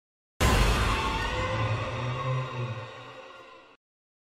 jumpscare.mp3